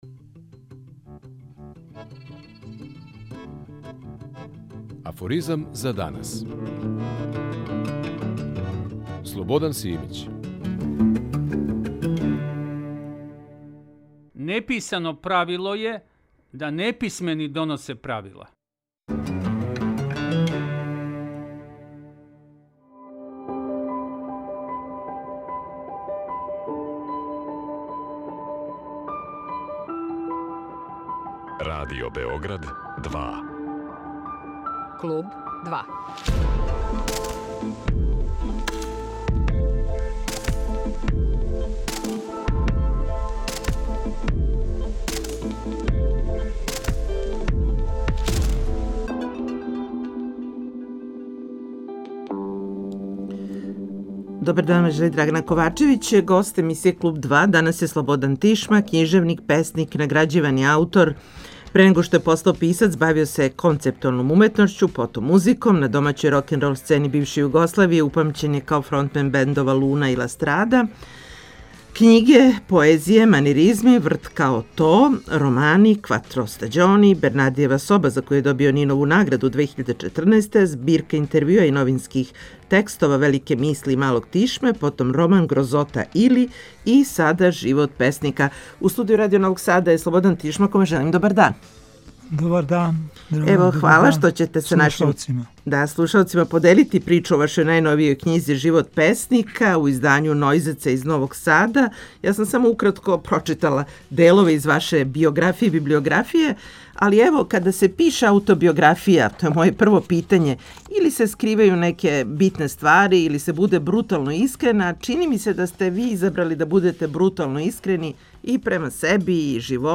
Гост емиисије Клуб 2 је писац и музичар Слободан Тишма.